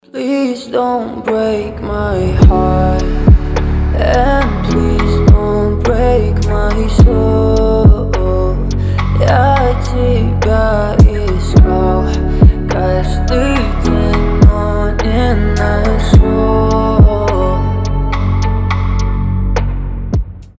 красивые
лирика
спокойные
медленные